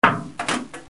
Sonido golpear objetos
Un ruido de impacto de los objetos que produce un ruido de caída de un segundo objeto.